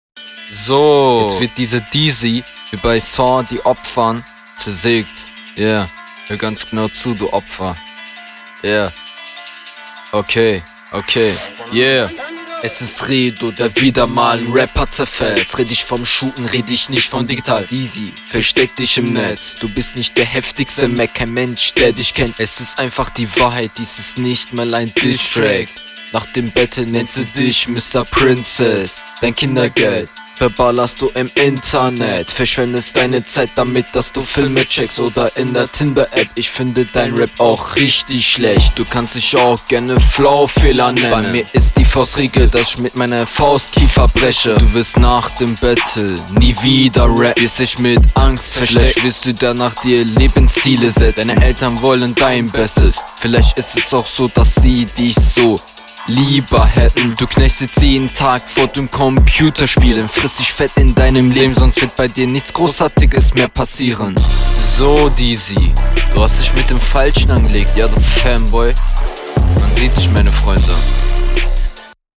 Flow: Diese Runde einen wesentlich besseren Flow auch wenn du immer noch zu viele Silben …